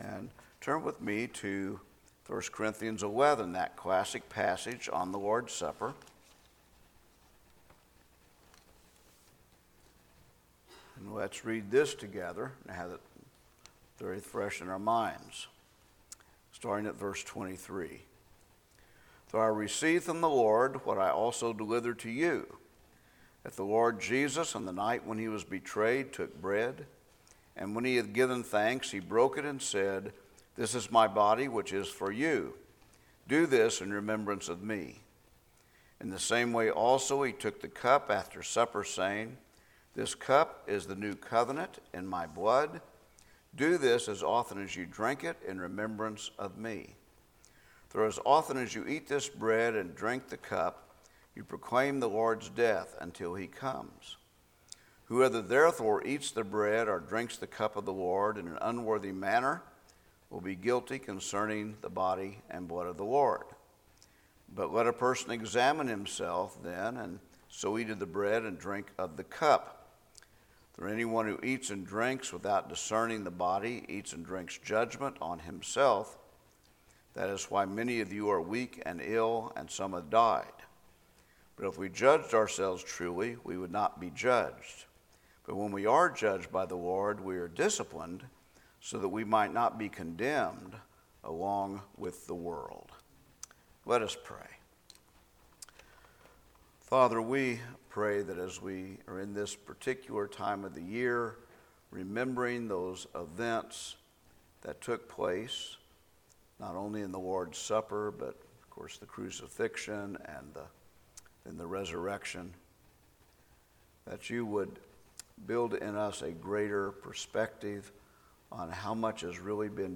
Easter 2026 Thursday Evening Service 4-2-2026 - Covenant of Grace Church